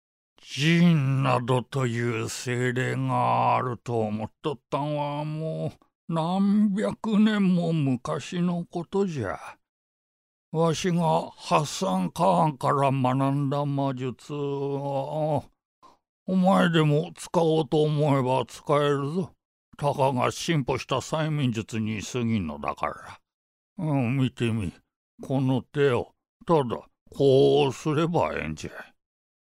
どことなく和を感じさせる低音。
ボイスサンプル6（おじいちゃん） [↓DOWNLOAD]